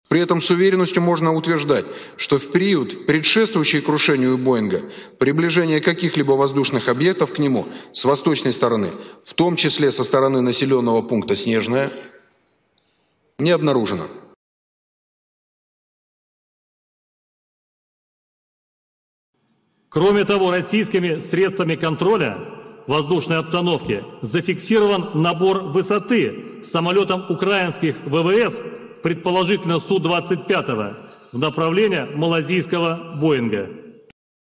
Брифинги в Минобороны РФ 26.09.2016 и 21.07.2014